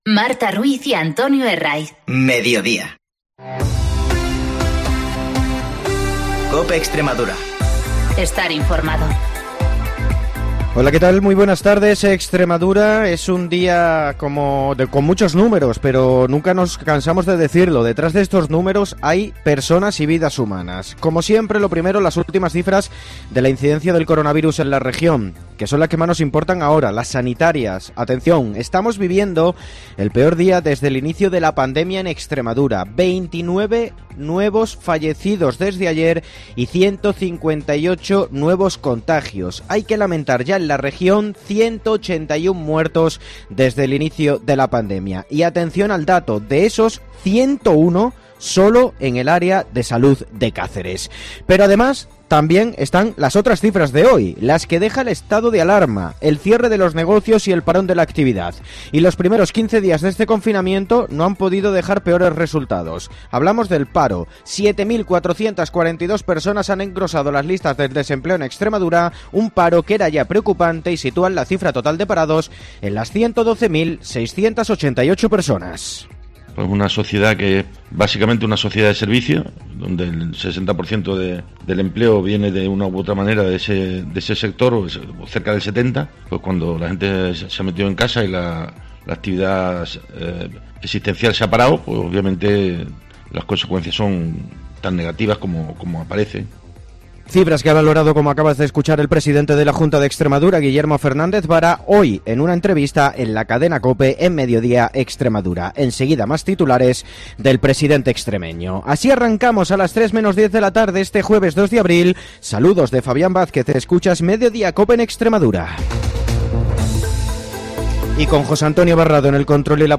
mediante teletrabajo